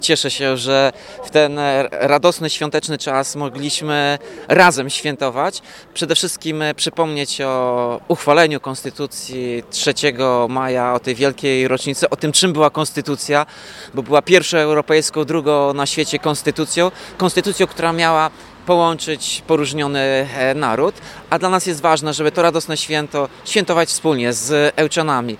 Uroczystości w deszczu
– To ważne, żebyśmy świętowali wspólnie i to nam się udało, mimo brzydkiej pogody mieszkańcy miasta pojawili się pod pomnikiem – mówi Tomasz Andrukiewicz, prezydent Ełku.